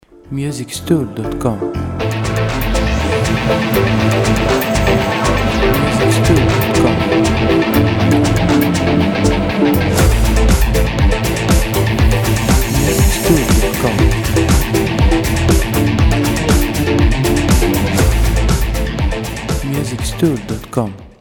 • Type : Instrumental
• Bpm : Allegro
• Genre : Disco/Funky / Techno / Riff Music Soundtrack